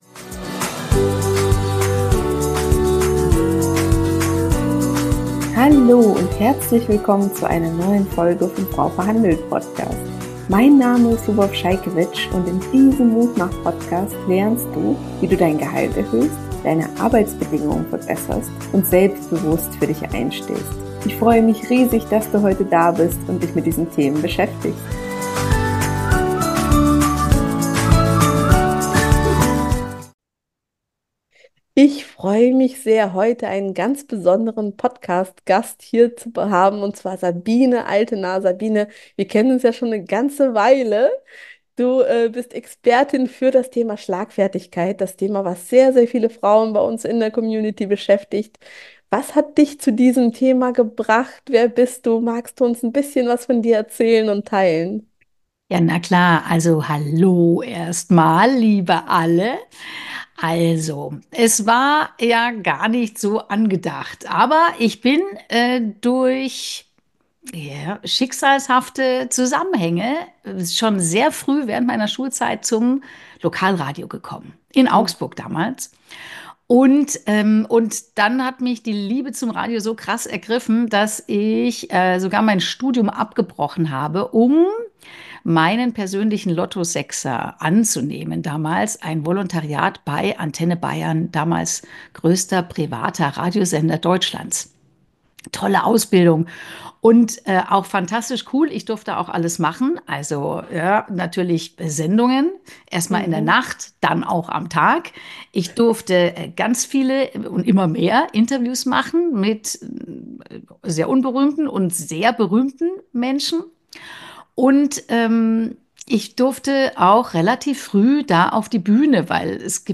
Techniken für mehr Schlagfertigkeit - Interview